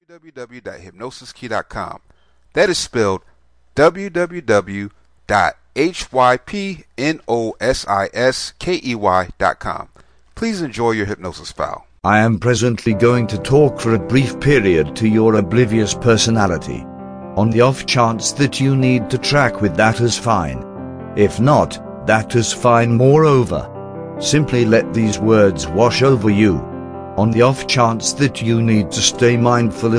Raynauds Disease Relaxation Self Hypnosis Mp3
RaynaudsDiseaseRelaxation.mp3